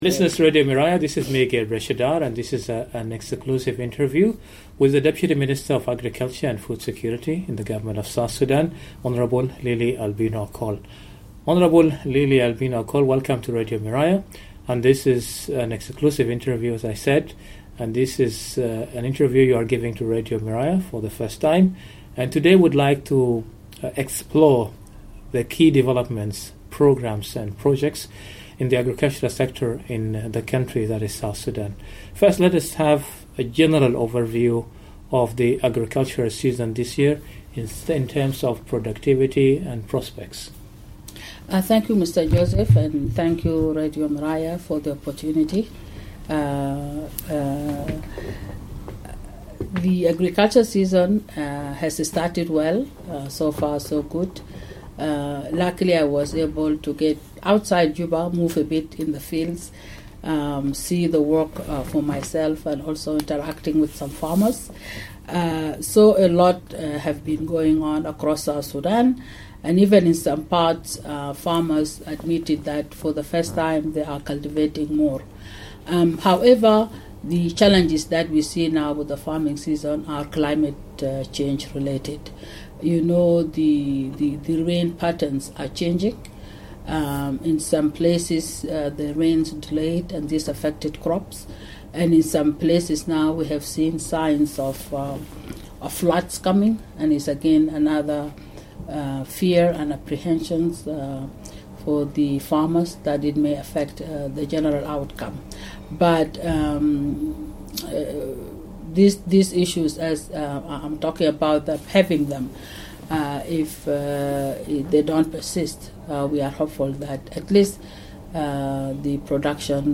Guest: Deputy Minister of the Ministry of Agriculture and Food Security, Hon Lily Albino Akol